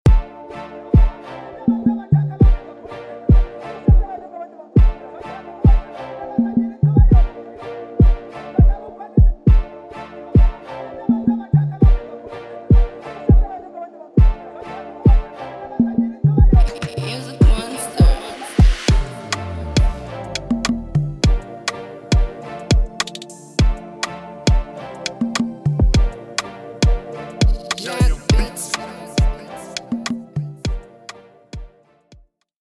Tempo: 102
Genre: Afrobeats